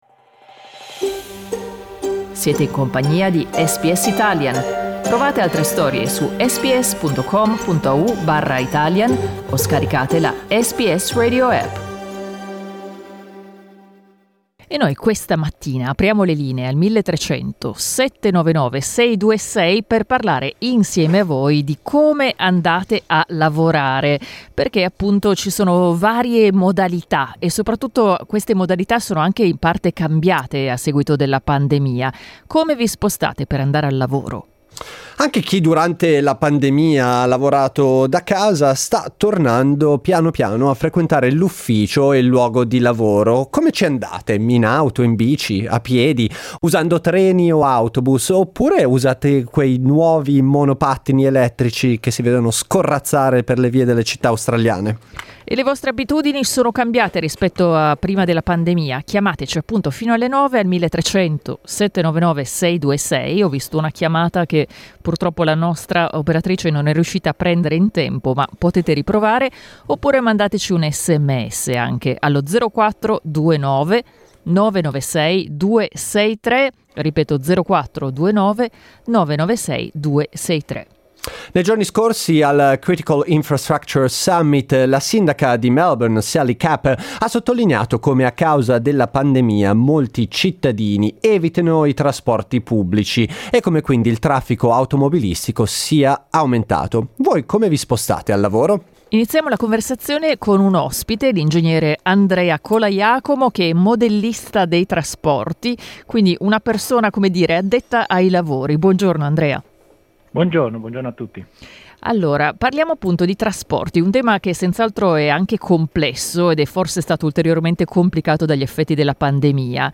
Ne abbiamo parlato con ascoltatori e ascoltatrici.